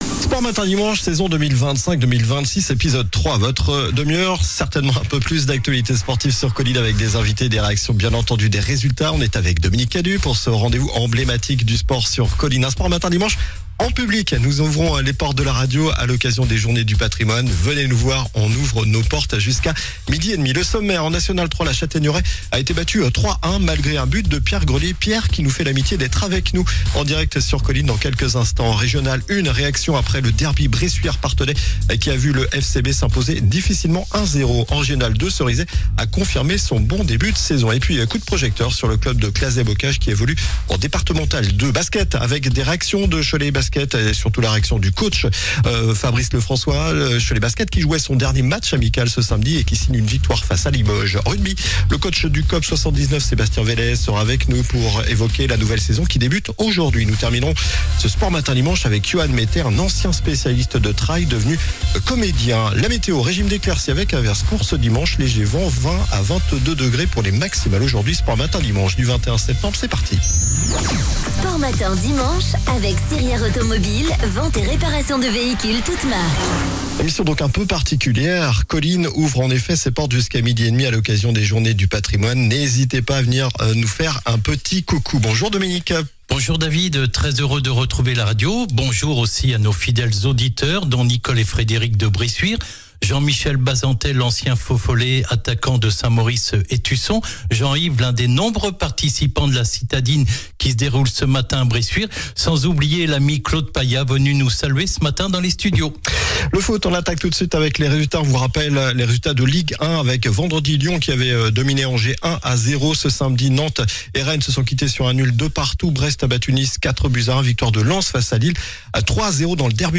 BASKET avec des réactions de Cholet Basket qui jouait son dernier match amical ce samedi et qui signe une victoire face à Limoges.